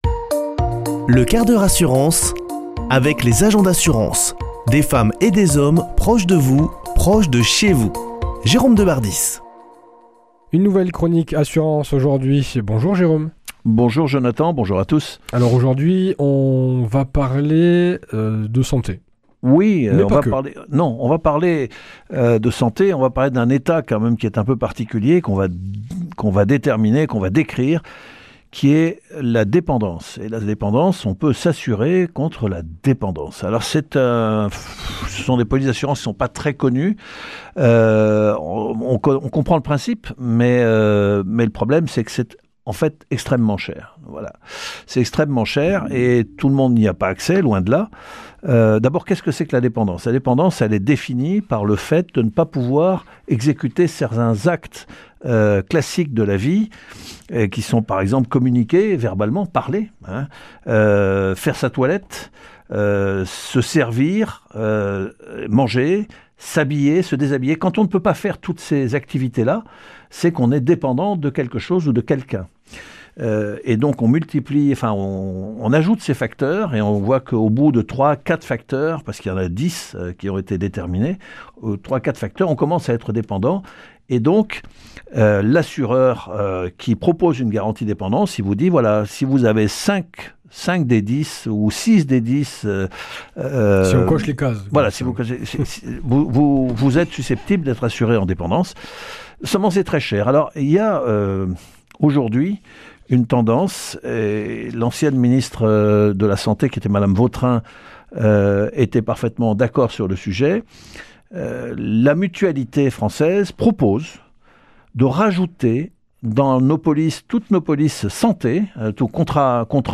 Chroniqueur